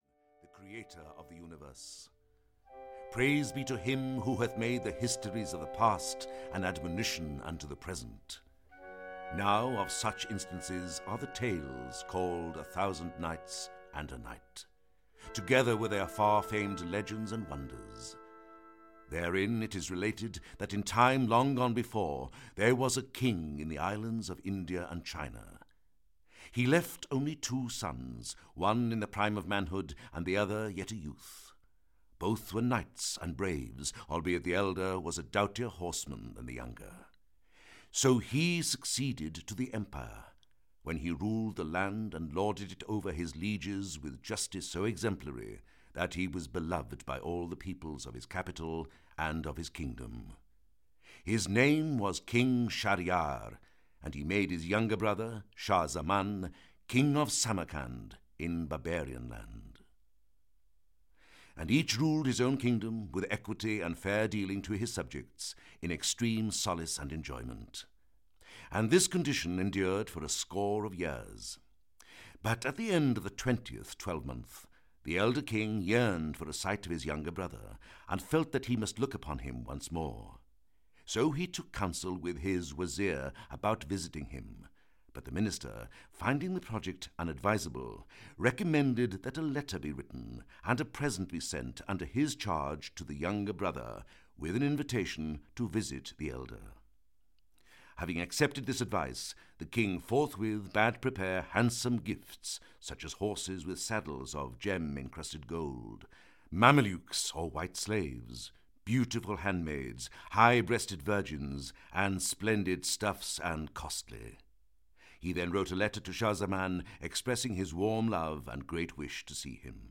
Audio kniha
Scheherazade must keep her king entertained with stories if she is to avoid the promised sentence of death. Philip Madoc’s sonorous performance allows the tales to weave their own enchantment as they have done down the centuries.